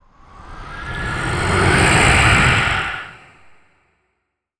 dark_wind_growls_01.wav